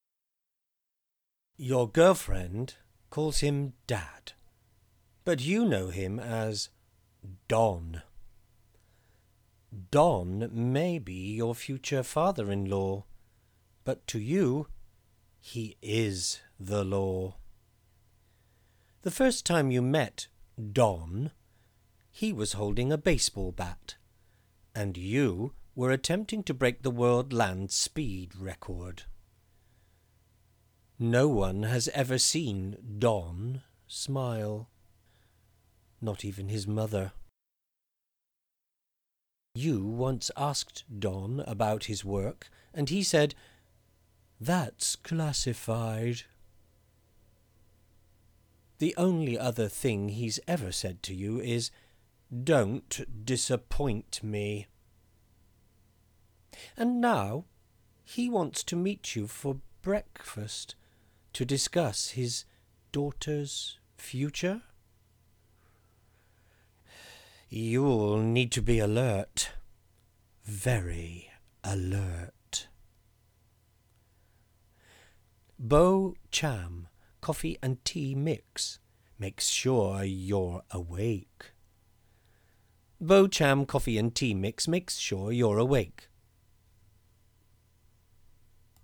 General UK & RP
I have my own home studio and can record with a regionally neutral UK accent or if needed a Heightened RP.